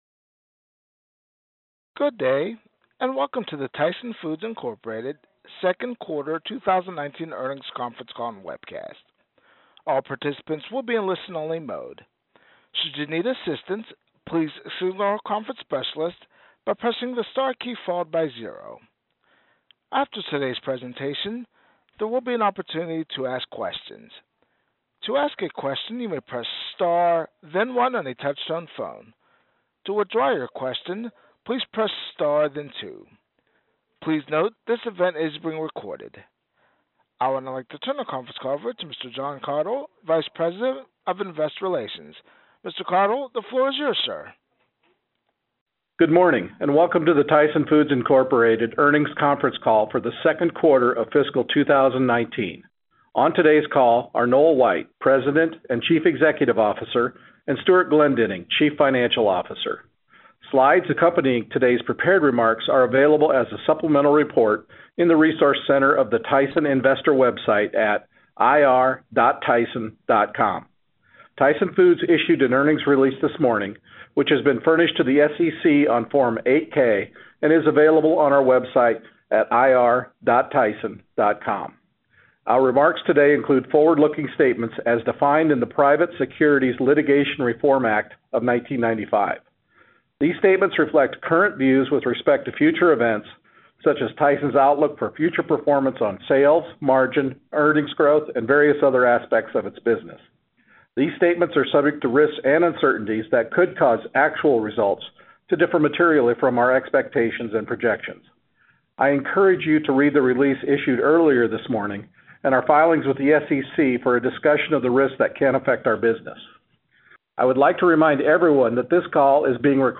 Tyson Foods Inc. - Q2 2019 Tyson Foods Earnings Conference Call